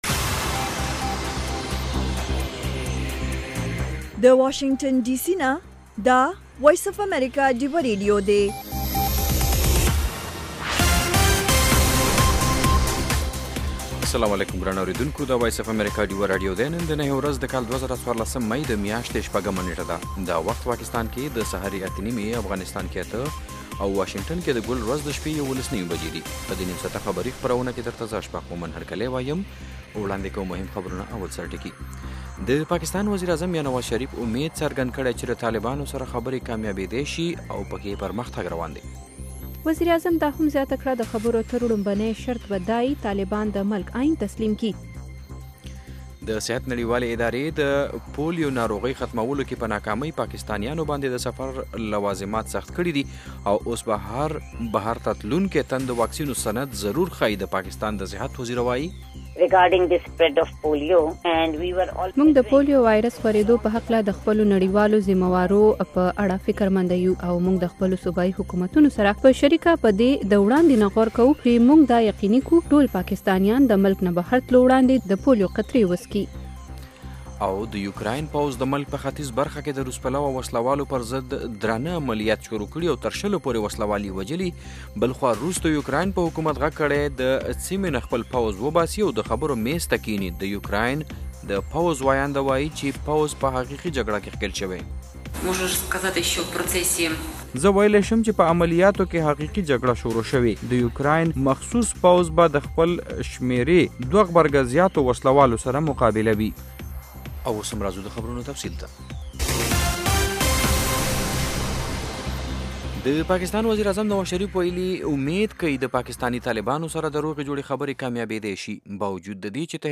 د وی او اې ډيوه راډيو سهرنې خبرونه چالان کړئ اؤ د ورځې دمهمو تازه خبرونو سرليکونه واورئ.